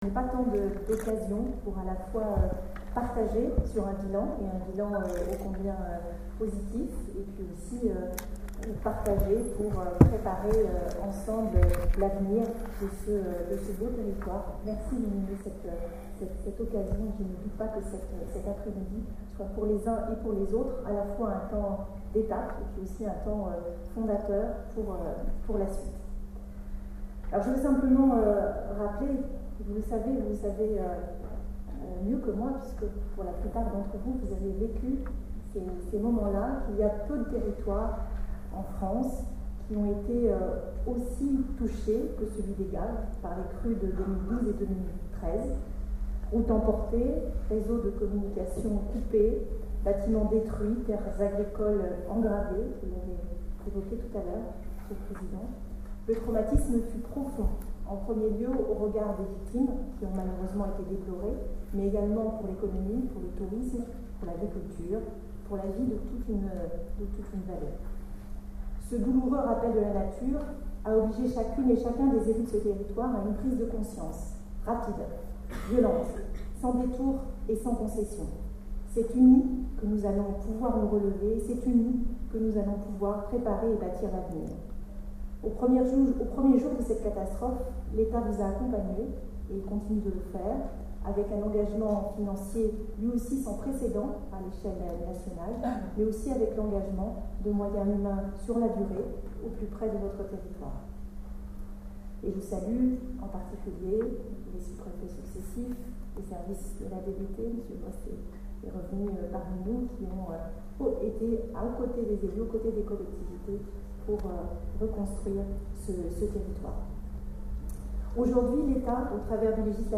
Ces Assises qui se sont déroulées, hier après-midi au palais des congrès de Lourdes, ont été suivies du lancement du nouveau Contrat de Rivière et du Programme d’ Actions de Prévention des inondations par les partenaires et maîtres d’ ouvrage.
Ouverture : les autres interventions
Anne-Gaëlle Baudouin-Clerc, préfète des Hautes-Pyrénées (